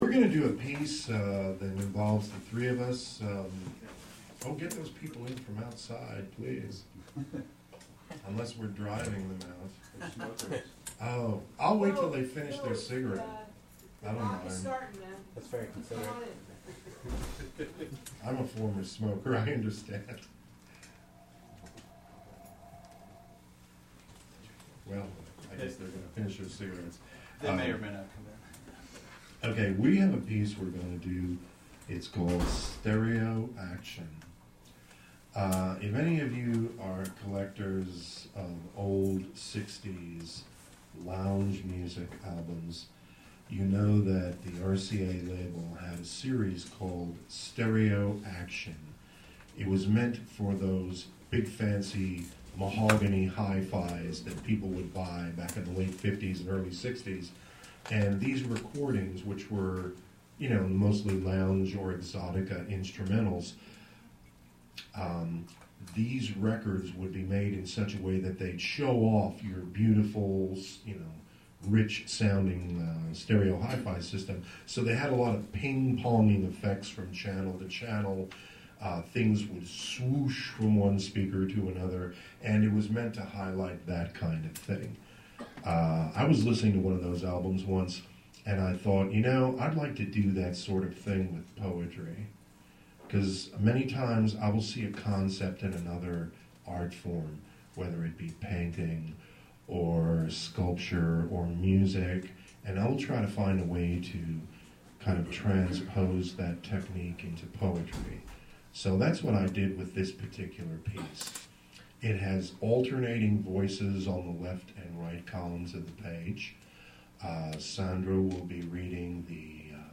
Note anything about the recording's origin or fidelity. at Upstate Artist Guild.